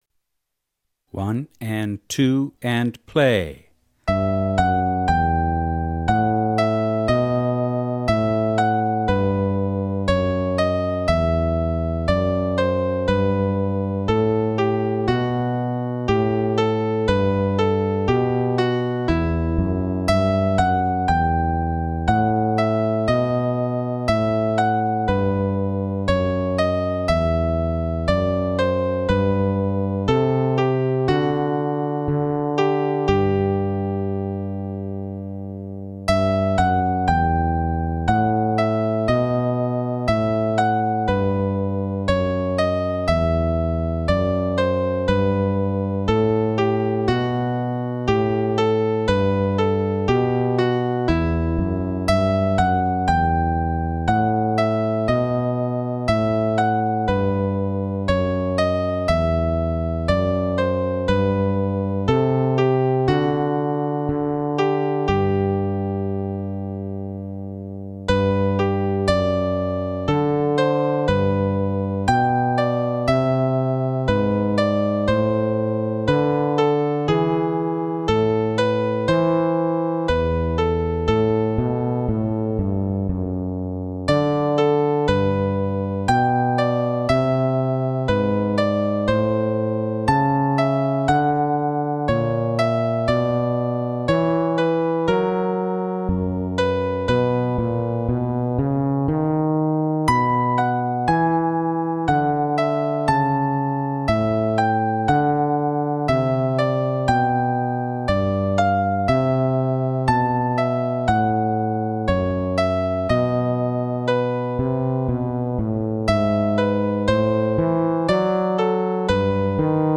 49 49-Bourree (slow).m4a